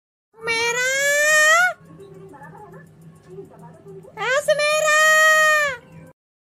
Goat Calling